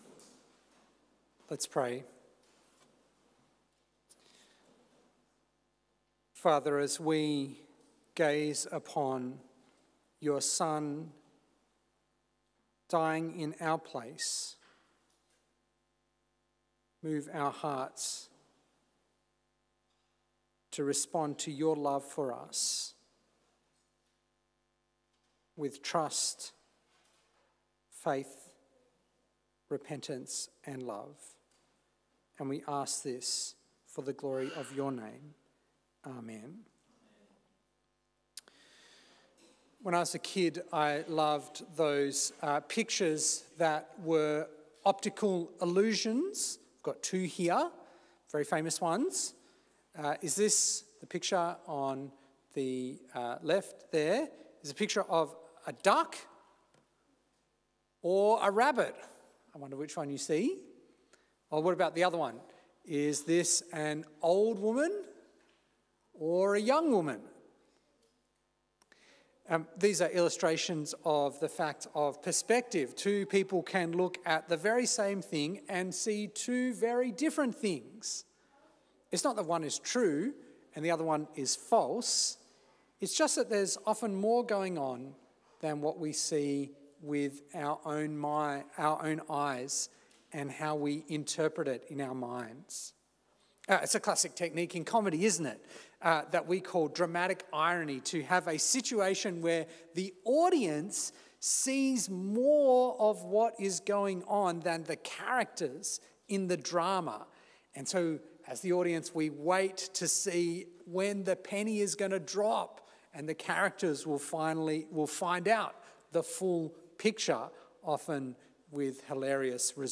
A Good Friday Sermon on Matthew 27
St-Georges-Battery-Point-Good-Friday-Service-2026.m4a